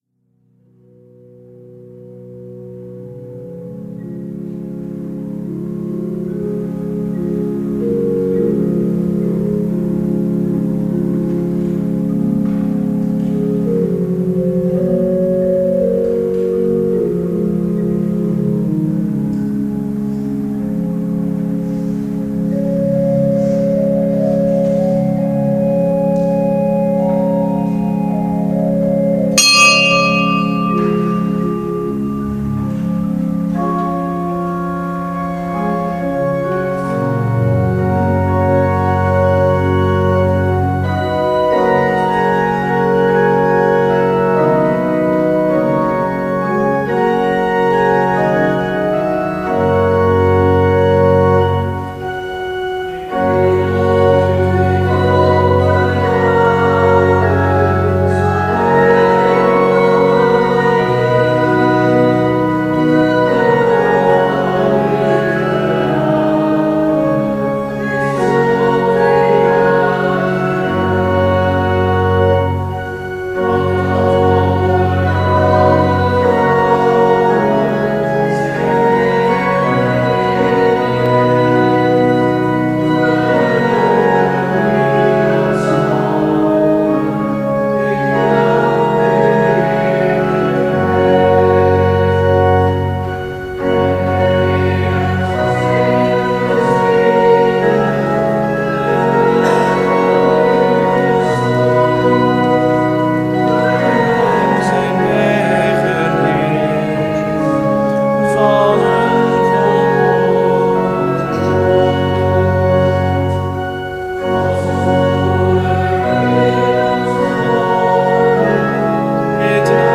Lezingen
Eucharistieviering beluisteren vanuit de Jozefkerk te Wassenaar (MP3)